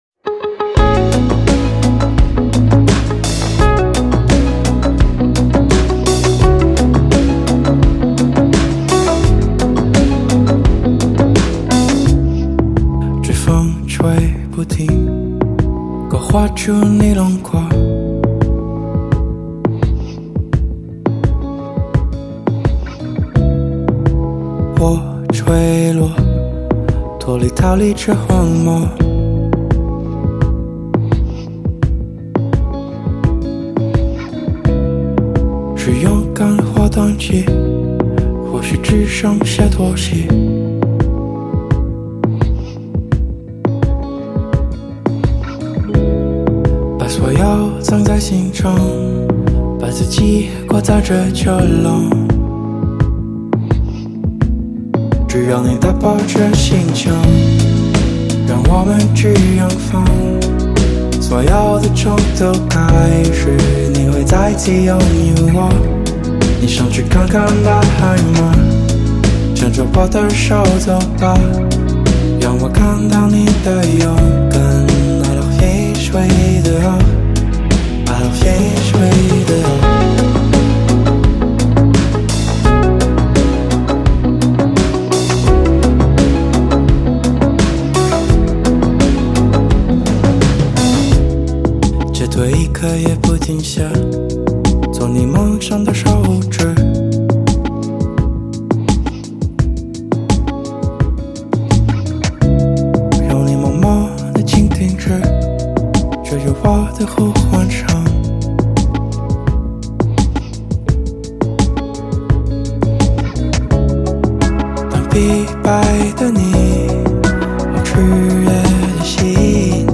Ps：在线试听为压缩音质节选，体验无损音质请下载完整版 这风吹不停勾画出你轮廓 我坠落脱离逃离这荒漠 是勇敢或胆怯？